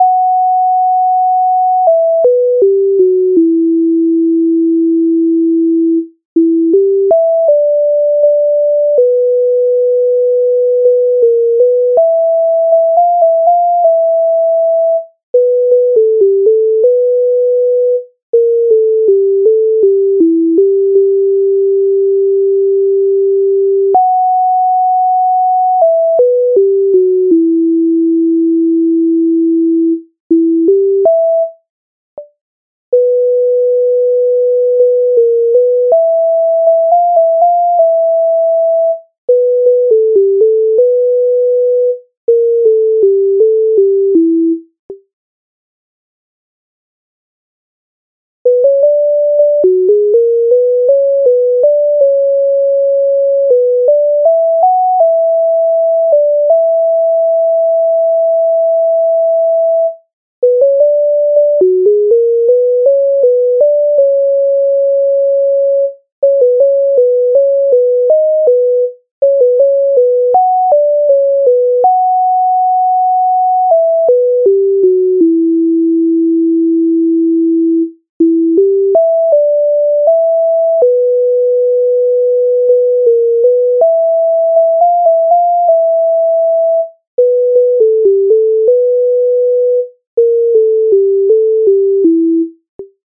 MIDI файл завантажено в тональності G-dur